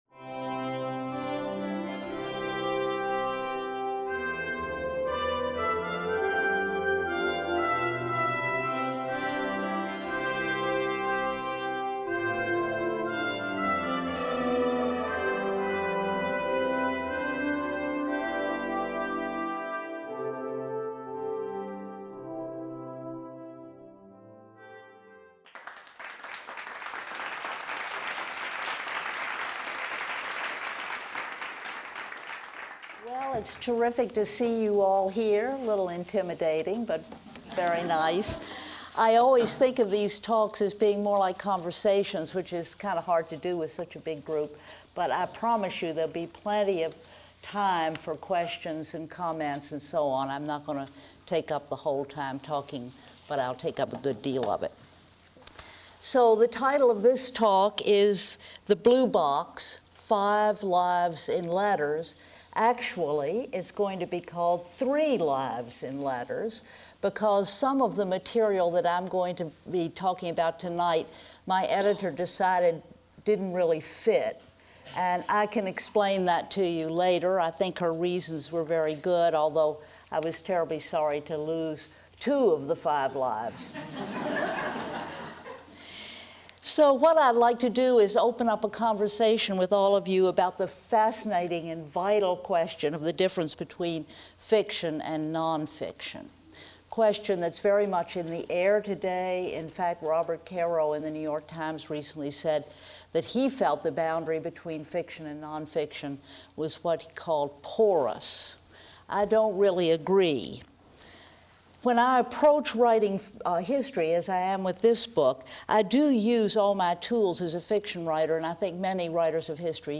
Sallie Bingham: The Blue Box: Five Lives in Letters. Recorded on May 15, 2012 at the Filson Historical Society, Louisville, Kentucky.